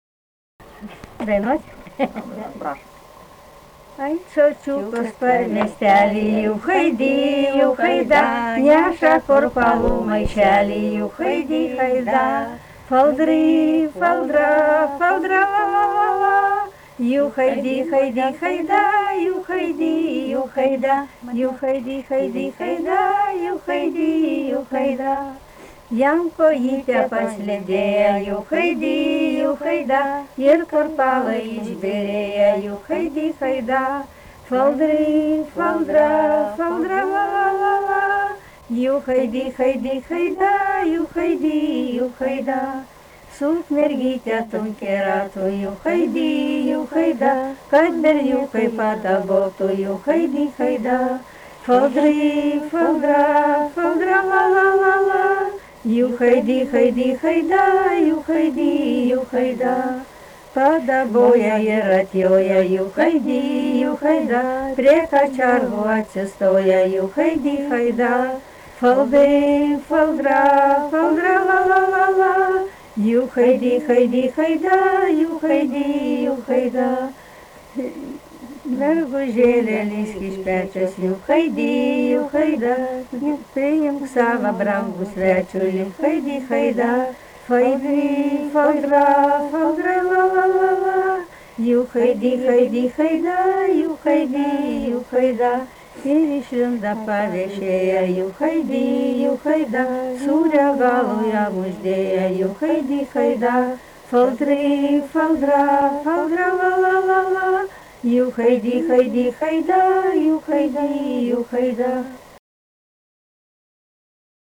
daina